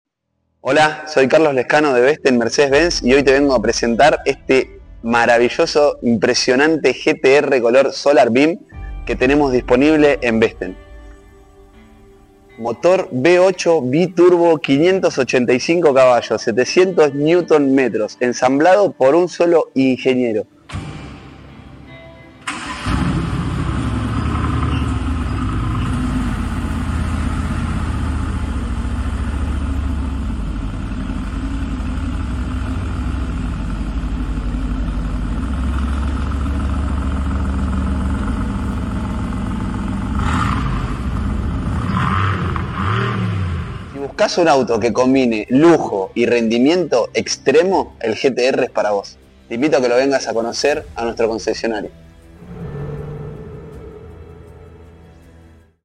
🔥 V8 Biturbo · Solo 8 en Argentina 📍Mercedes-Benz Vesten